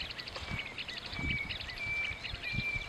Greater Wagtail-Tyrant (Stigmatura budytoides)
Province / Department: Tucumán
Location or protected area: Trancas
Condition: Wild
Certainty: Photographed, Recorded vocal